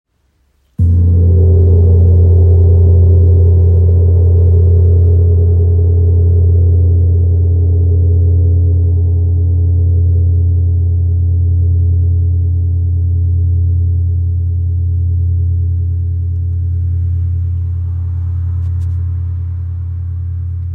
Large Gong with Om Design – 67cm
Every strike produces deep, resonant tones that ripple through the body and mind, fostering clarity, balance, and profound relaxation.
Its impressive size allows for a powerful and immersive sound experience, making it ideal for sound baths, meditation sessions, or energy healing.
Gong.mp3